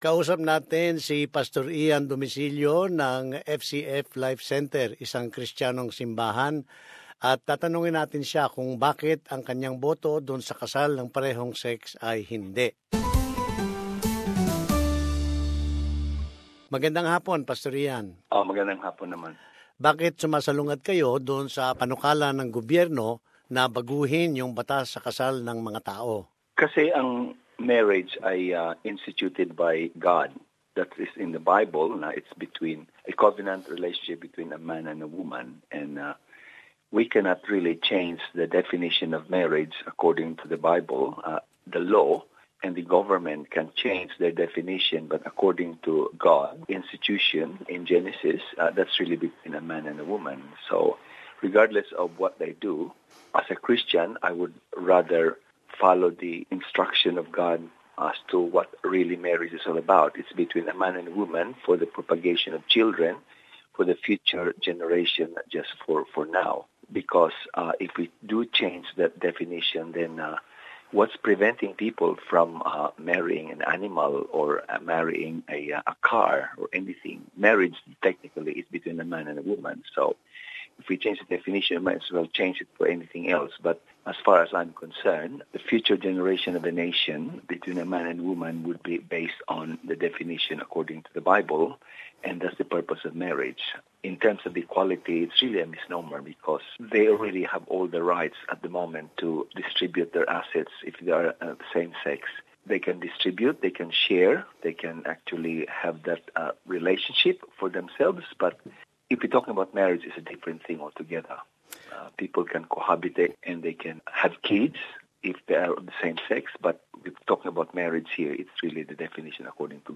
Sinabi niya sa isang panayam na boboto siya ng HINDI o NO, sa pagbabago ng batas sa kasal.